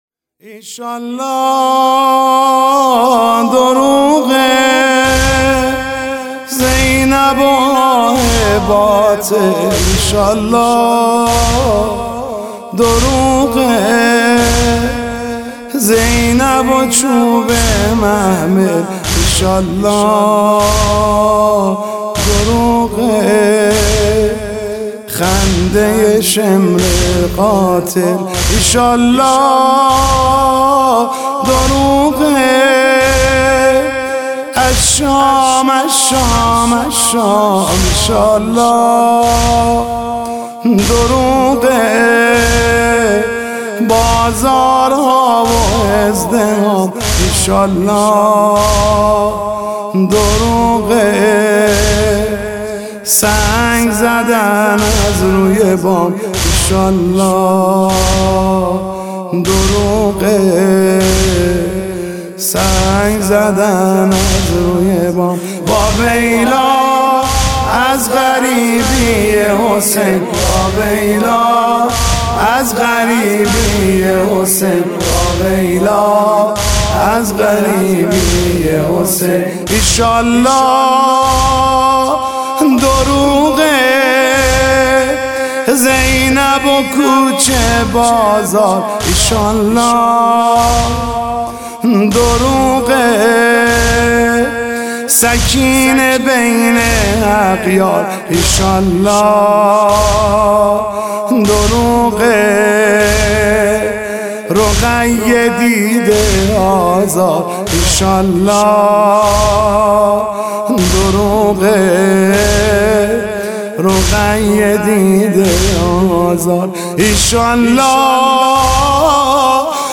نوحه ایشالله دروغه زینبُ آهه باطل